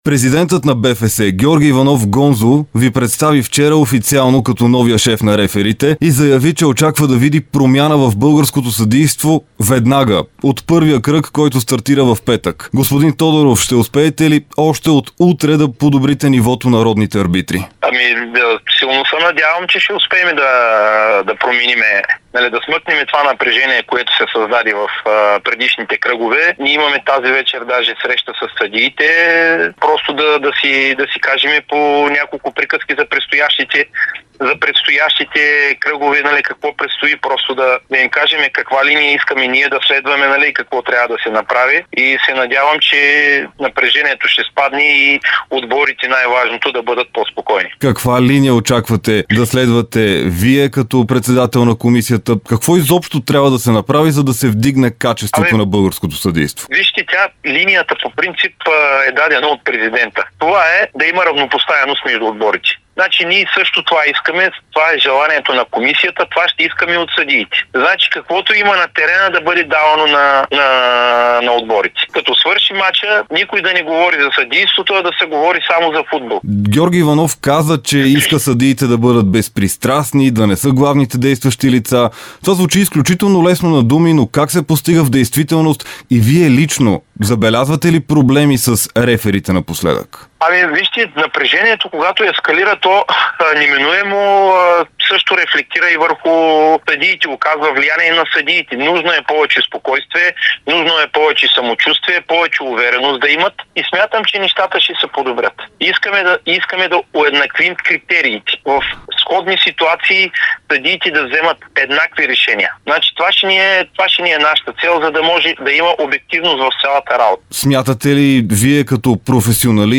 даде интервю за Дарик радио и dsport.